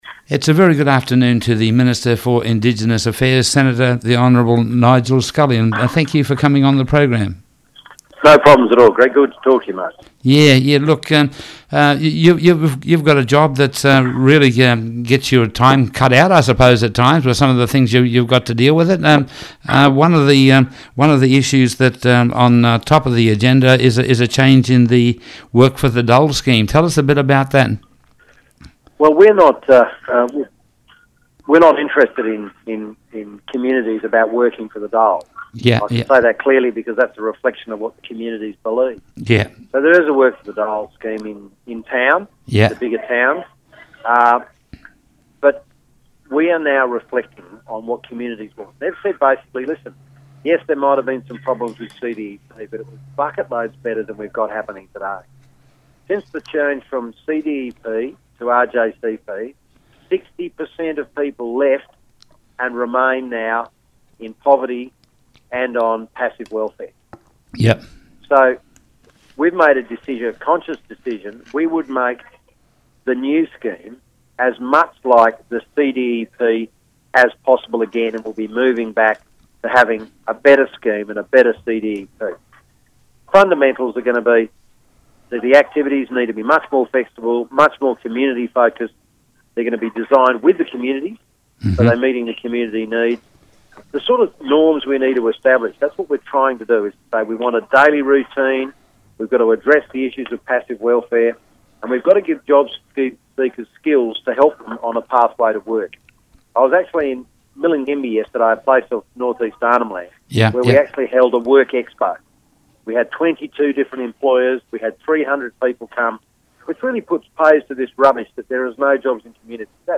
If you missed the interview on today’s Morning Focus, listen to it here in full.